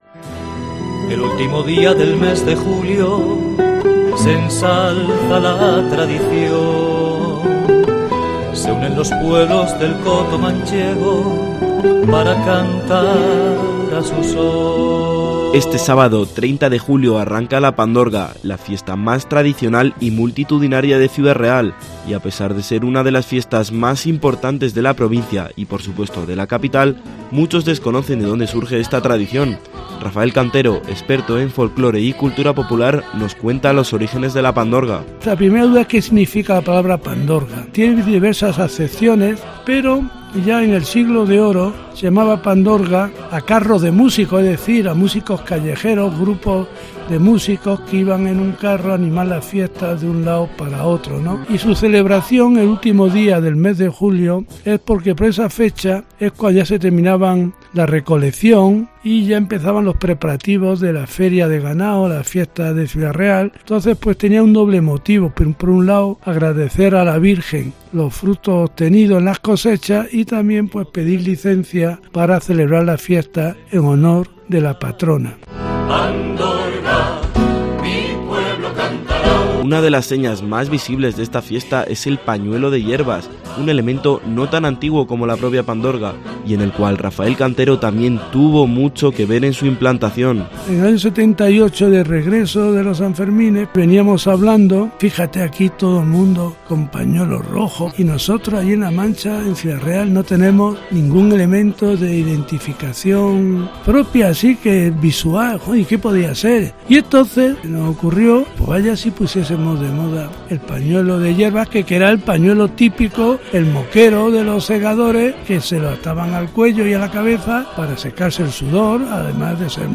Reportaje sobre la Pandorga de Ciudad Real